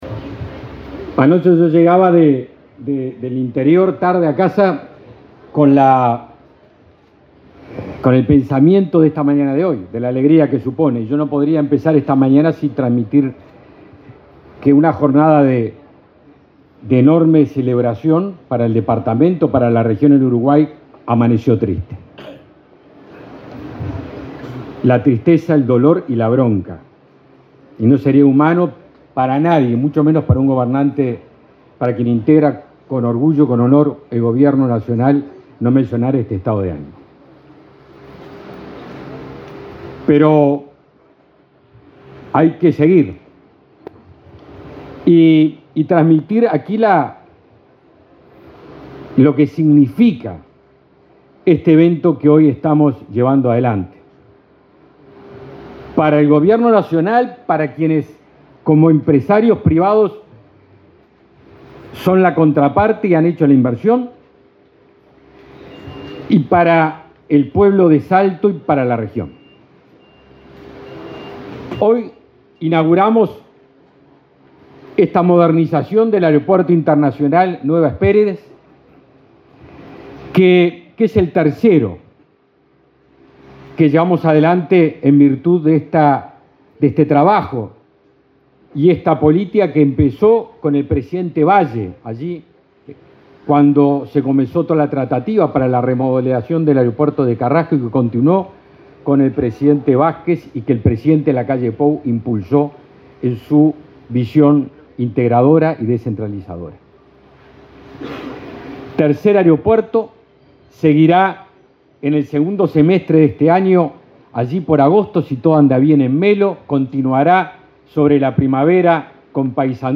Palabras del ministro de Defensa Nacional, Javier García
El ministro de Defensa Nacional, Javier García, participó, este viernes 23, en la inauguración del aeropuerto internacional de Salto.